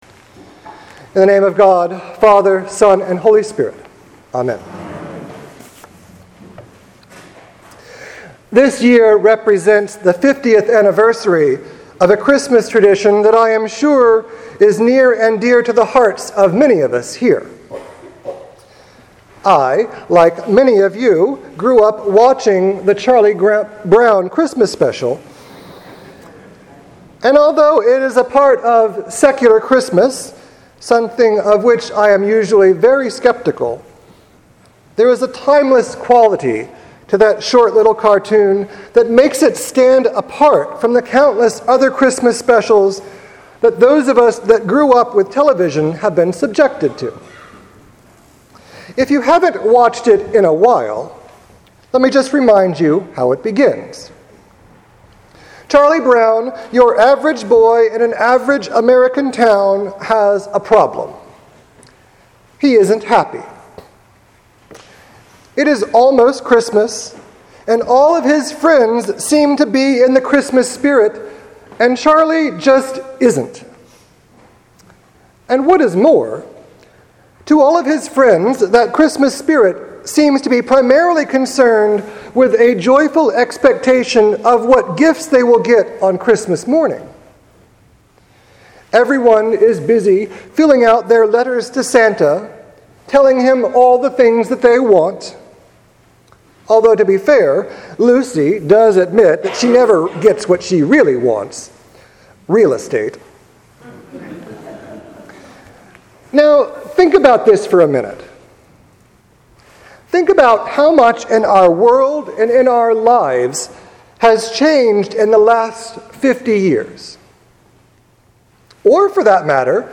Sermon preached at The Church of the Ascension on December 6th, 2015.